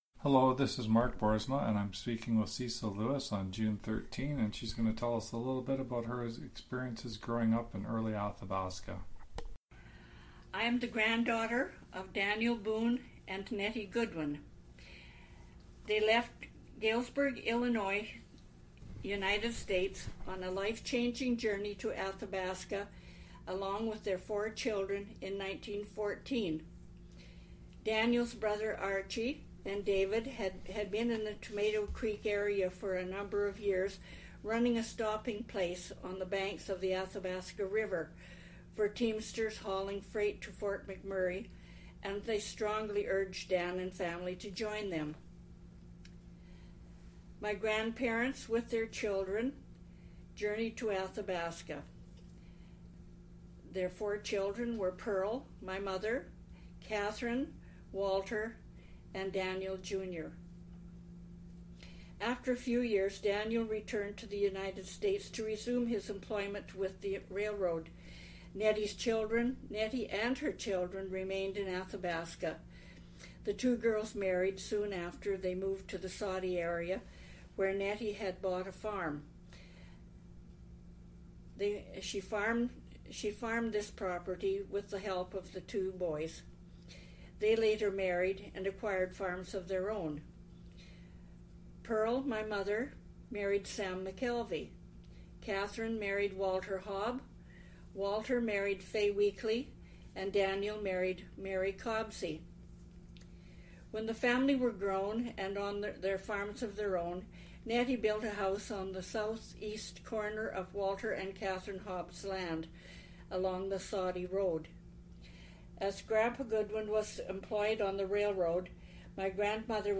Audio interview,